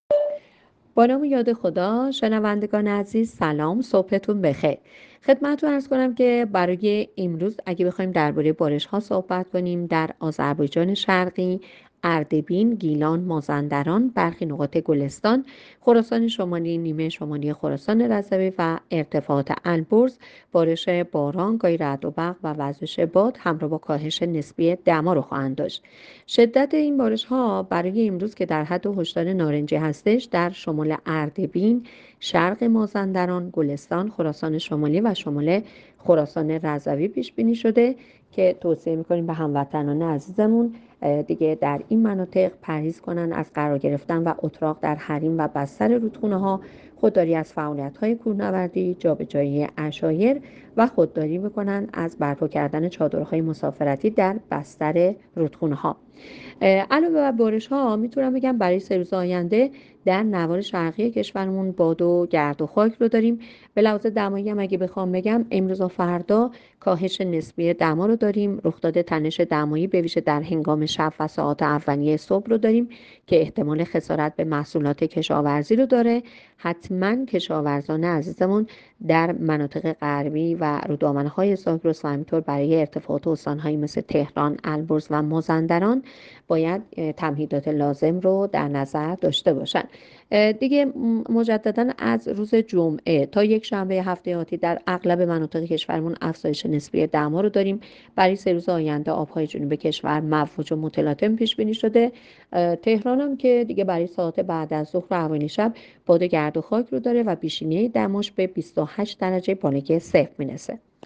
گزارش رادیو اینترنتی پایگاه‌ خبری از آخرین وضعیت آب‌وهوای هفدهم اردیبهشت؛